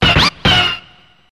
One of Yoshi's voice clips in Mario Party 2